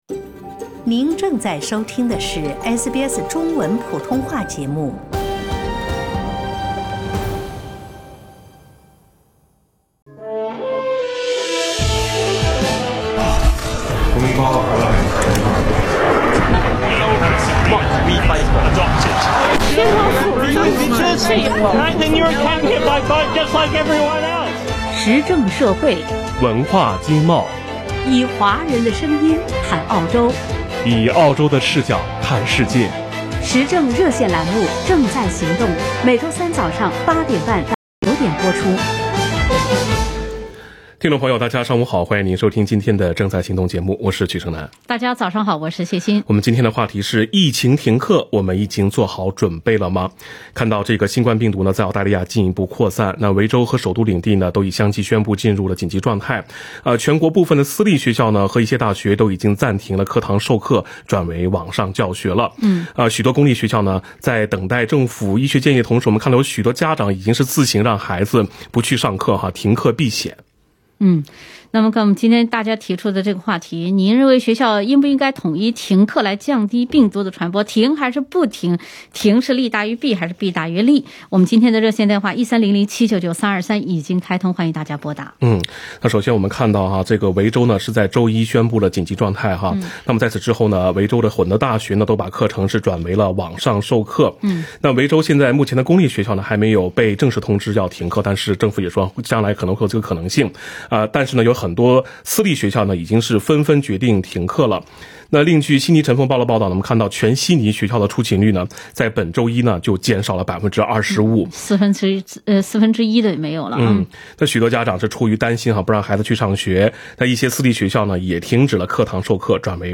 【COVID-19报道】防疫停课，澳洲人到底怎么想？热线大讨论